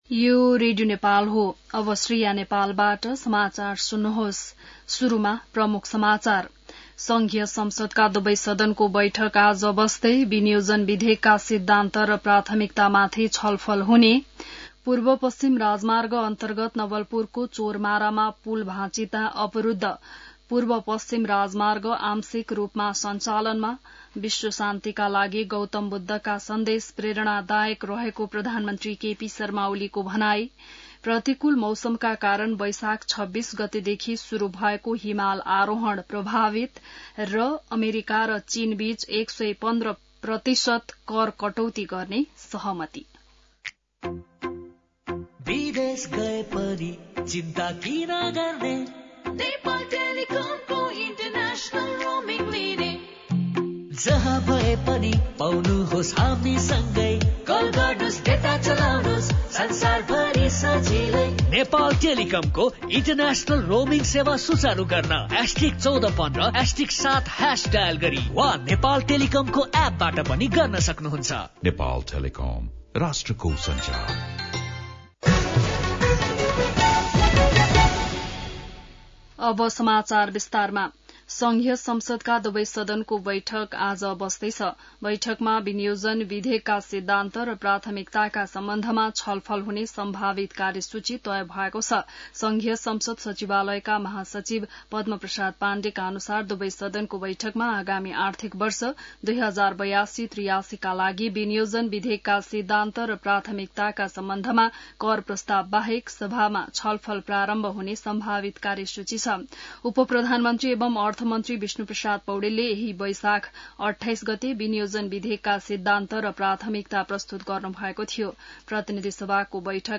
बिहान ७ बजेको नेपाली समाचार : ३० वैशाख , २०८२